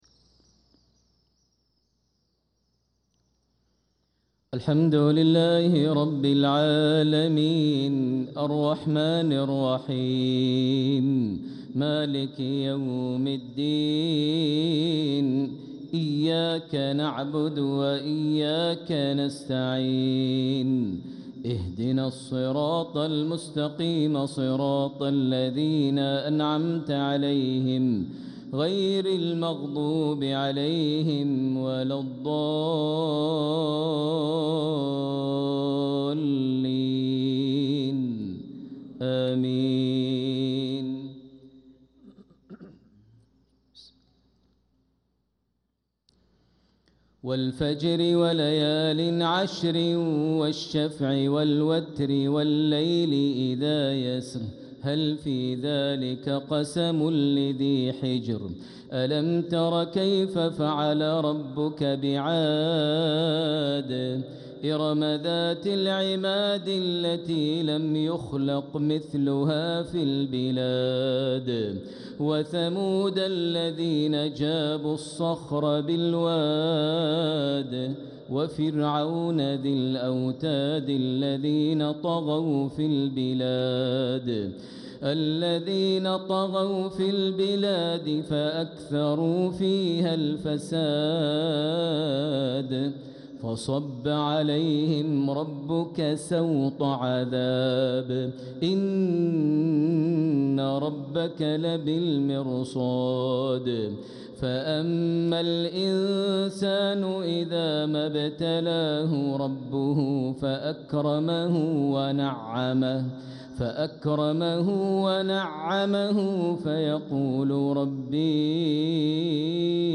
Maghrib prayer from Surat Al-Fajr | 9-5-2025 > 1446 H > Prayers - Maher Almuaiqly Recitations